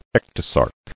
[ec·to·sarc]